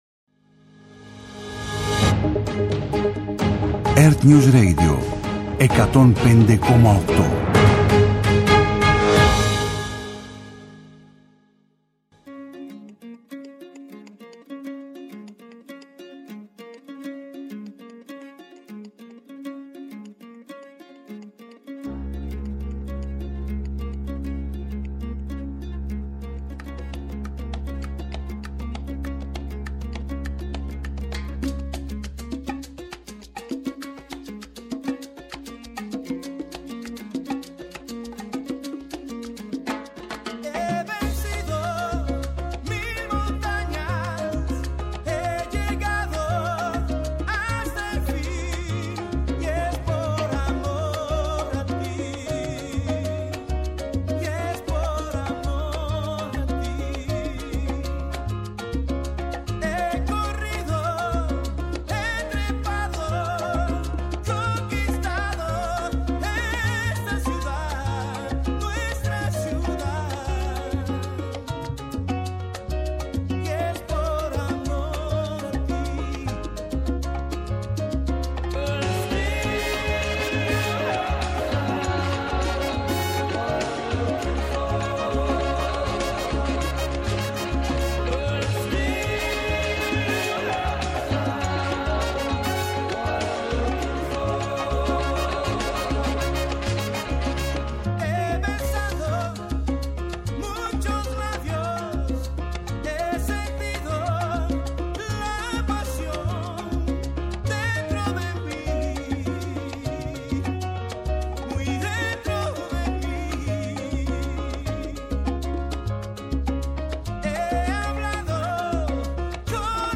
-Η Τάσος Χατζηβασιλείου, διεθνολόγος και βουλευτής ΝΔ